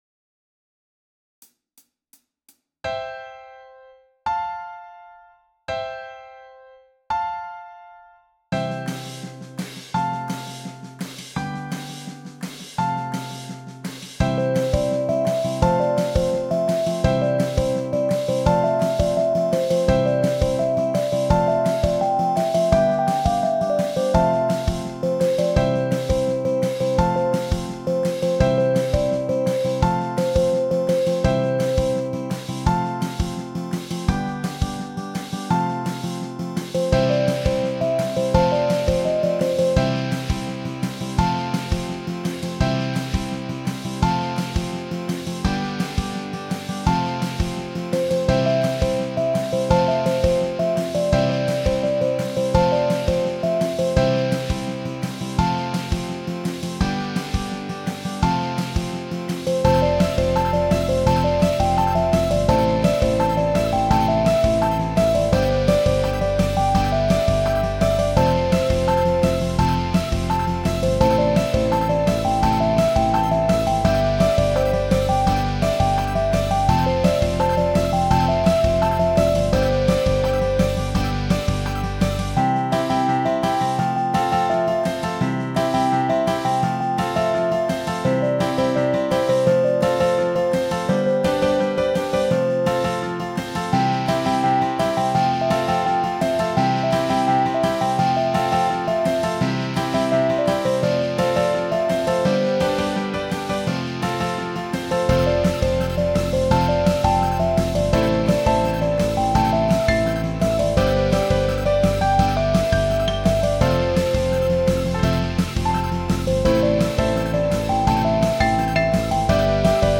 end_music.m4a